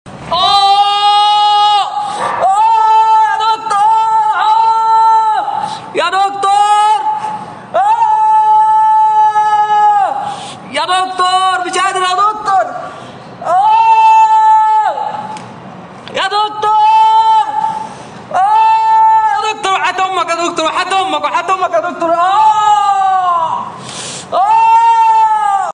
Yeah sound effects free download